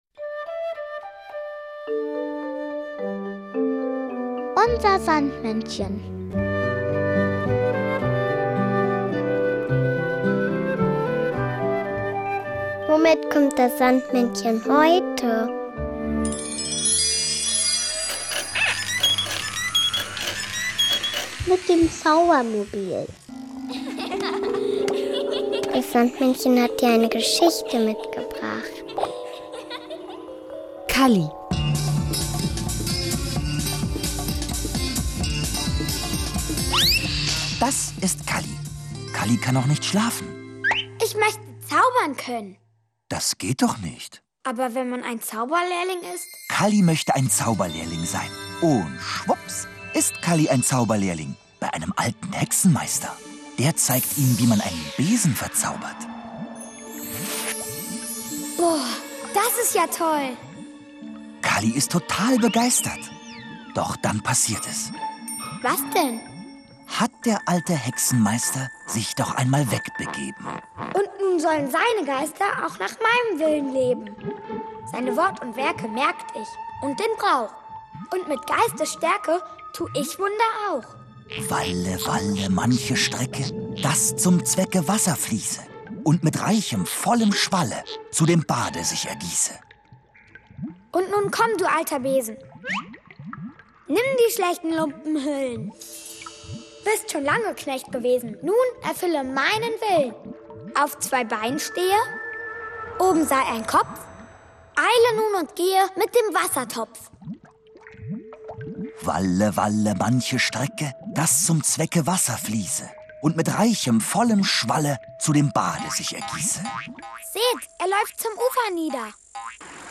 Kinderlied